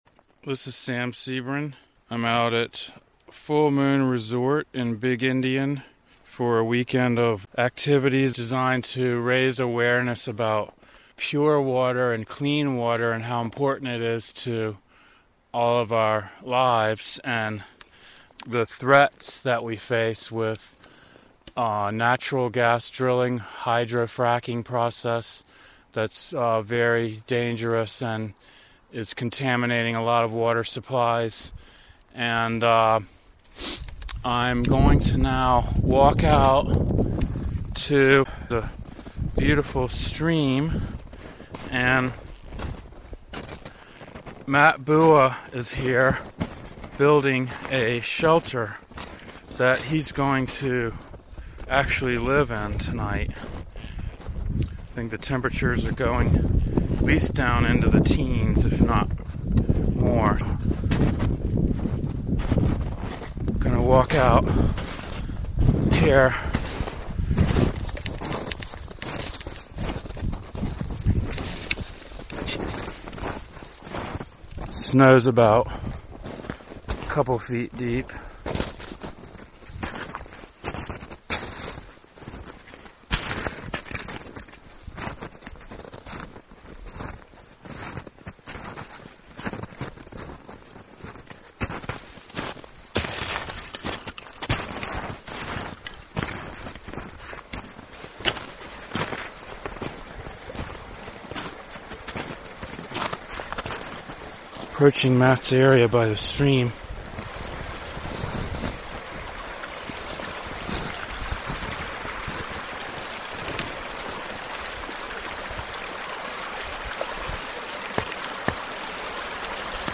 Editing clips from three days of field recordings